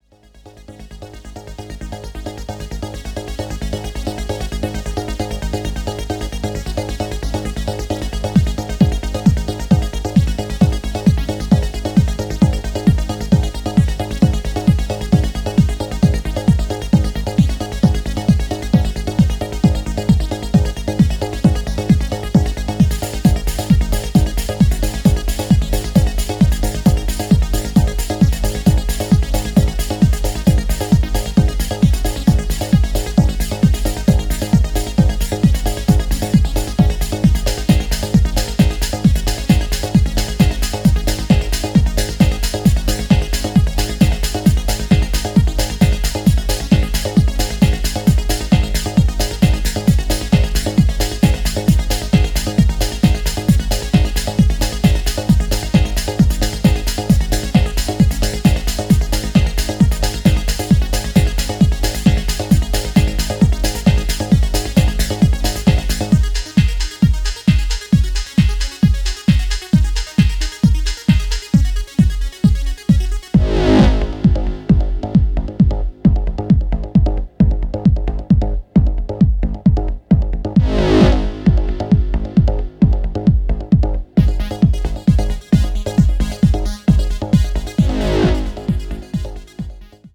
トランシーでプログレッシヴなハウスを程よくミニマルに繰り広げていった、派手さやエグ味は控えめな仕上がりとなっています。